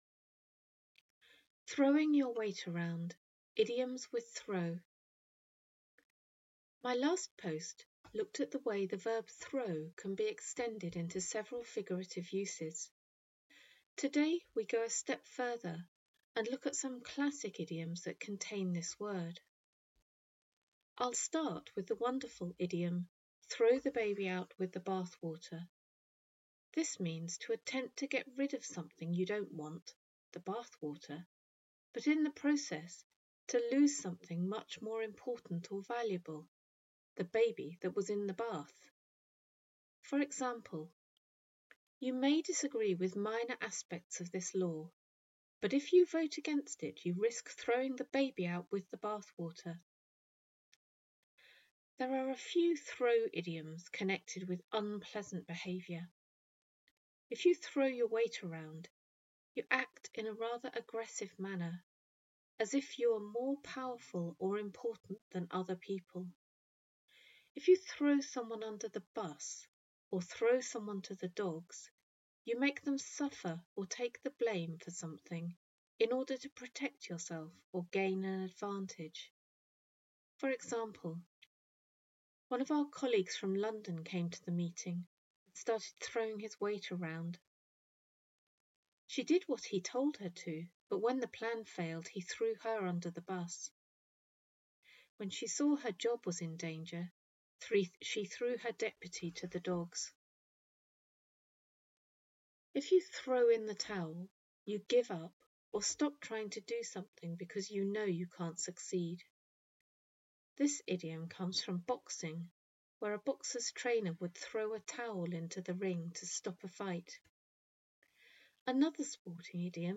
Listen to the author reading this post.